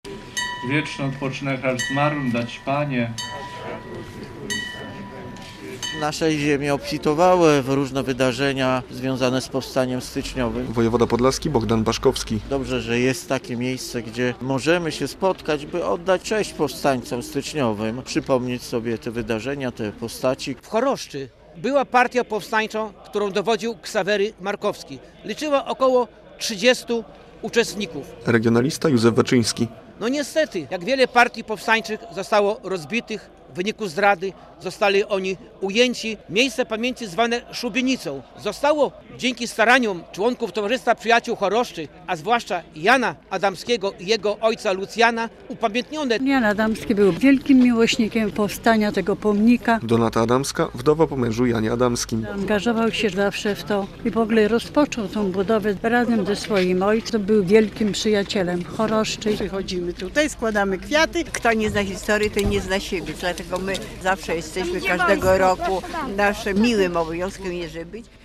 159. rocznica wybuchu powstania styczniowego - uroczystości w Choroszczy - relacja